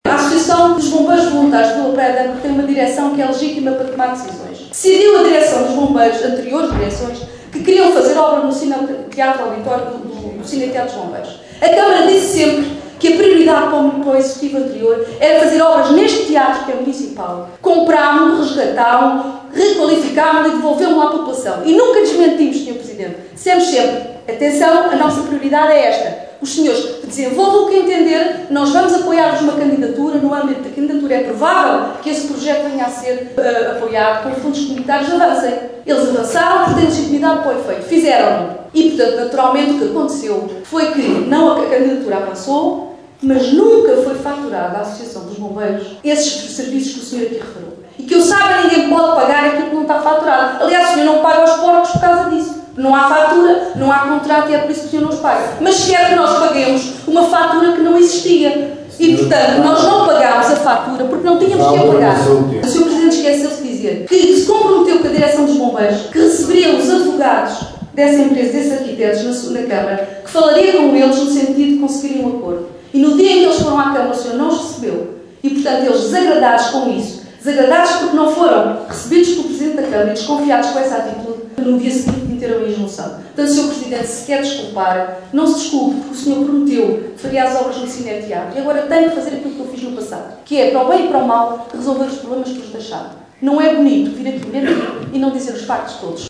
Agora deputada municipal, a anterior presidente da Câmara de Caminha, Júlia Paula, acusou o actual presidente, o socialista Miguel Alves, de estar a mentir. Júlia Paula explicou que o anterior executivo camarário nunca considerou as obras no cineteatro dos bombeiros de Vila Praia de Âncora uma prioridade e que não apoiou os bombeiros no pagamento dos projectos porque os serviços dos arquitectos nunca foram facturados.
assembleia-municipal-bombeiros-vpa-julia-paula.mp3